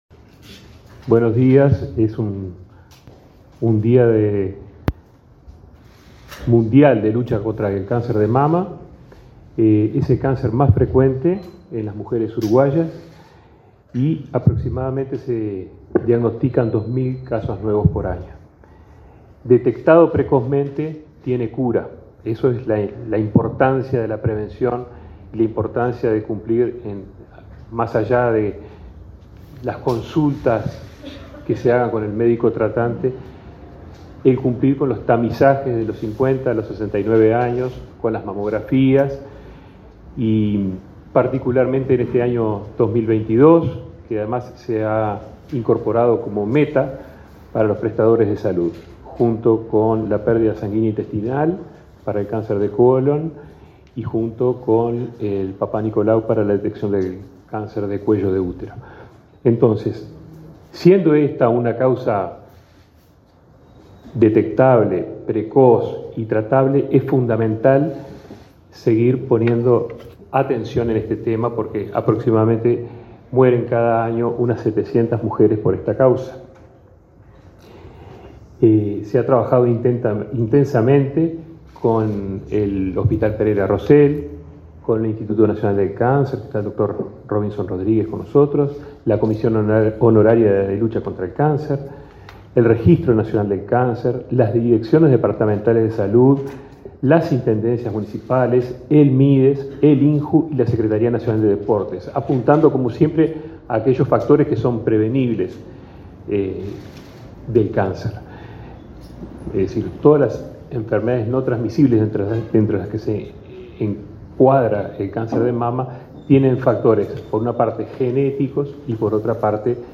Palabras de autoridades del MSP
Palabras de autoridades del MSP 10/10/2022 Compartir Facebook X Copiar enlace WhatsApp LinkedIn El titular del Ministerio de Salud Pública, Daniel Salinas, y la directora de Coordinación, Karina Rando, participaron en el acto por el Día Mundial de Lucha contra el Cáncer de Mama.